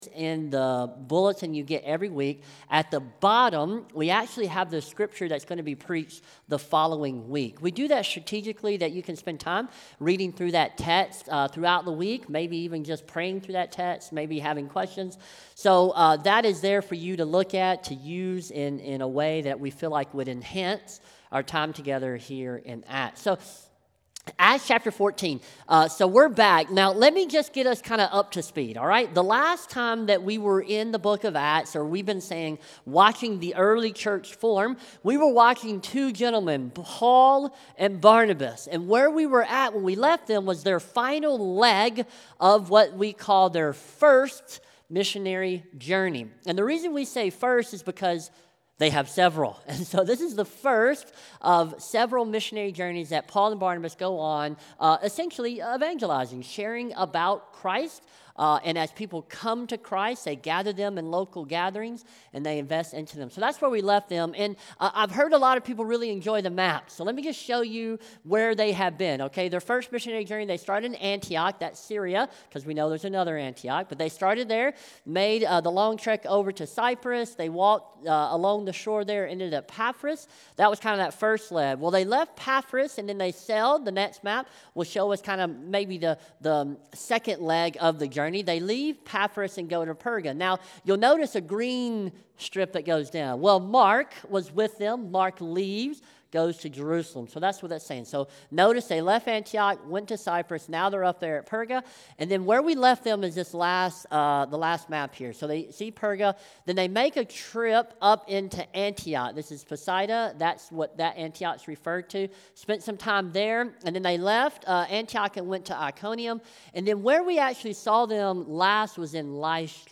SERMON | Acts 14:19-28 | Paul is Stoned | Light in the Desert Church